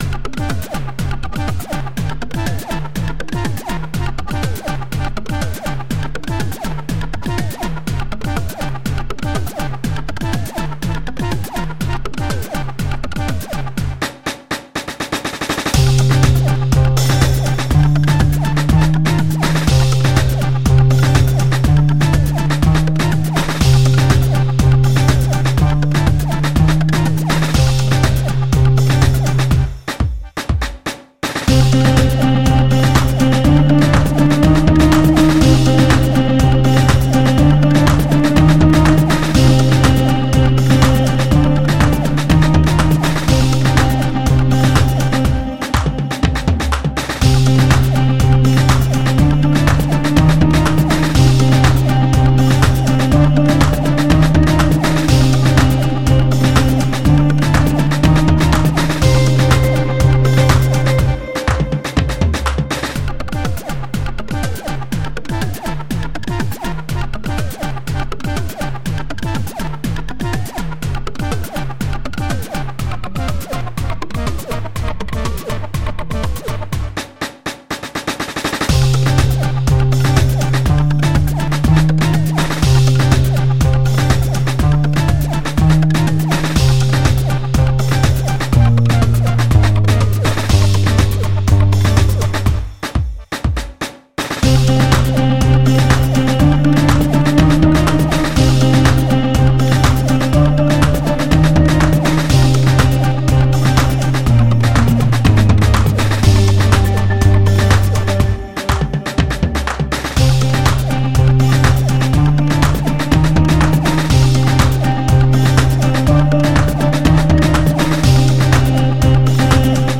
Native Instruments – Rounds, Drumlab, Bass, Guitar